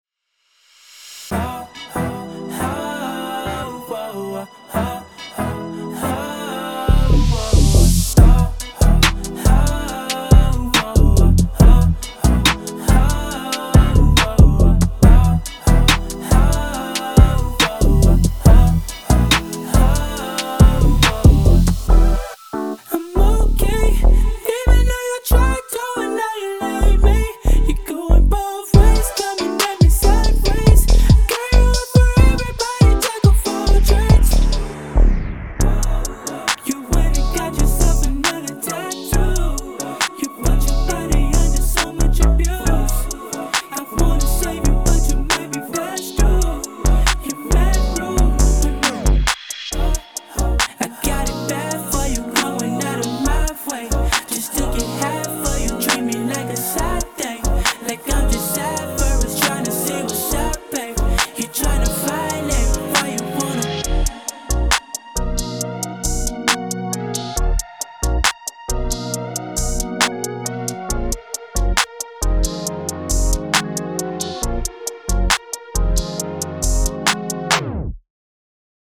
Rnb
在这个采样包中，你会找到所有假声主旋律，以及跳跃的贝斯和合成器进行，让你的音轨动起来！